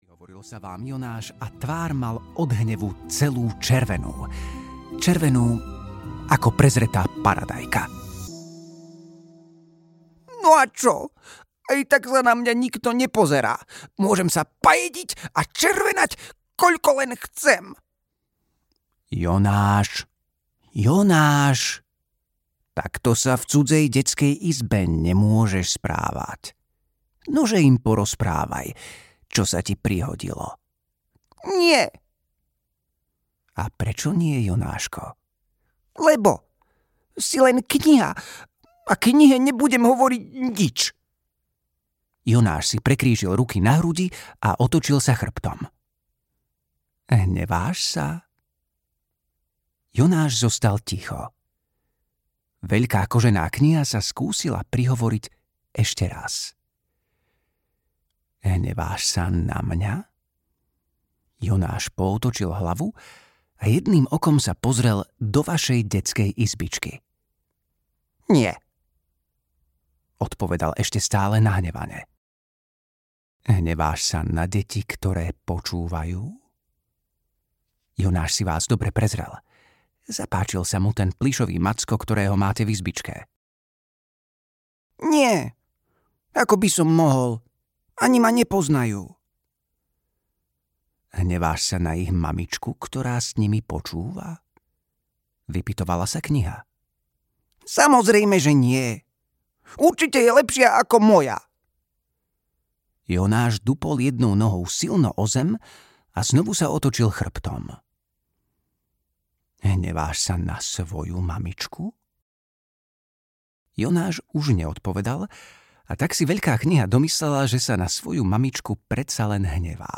Tajná kniha v nás audiokniha
Ukázka z knihy